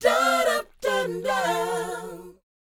DOWOP C 4F.wav